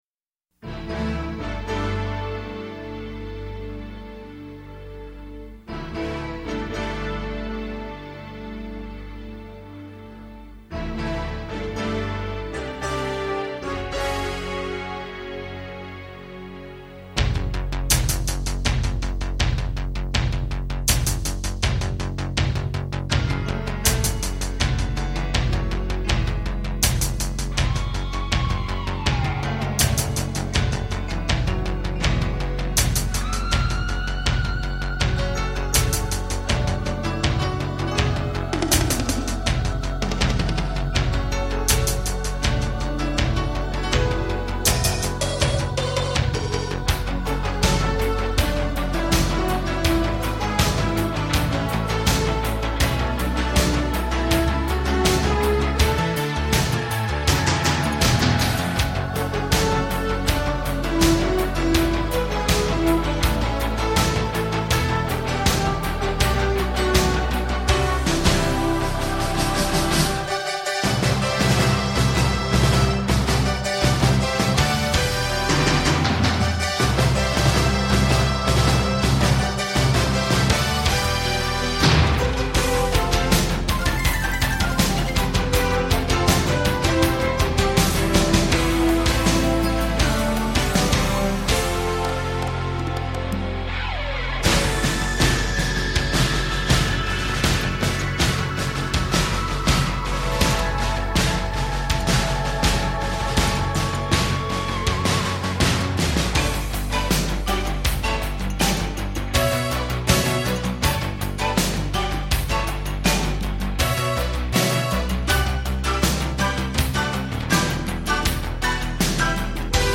C’est nigaud, kitsch sans doute, gras à lard.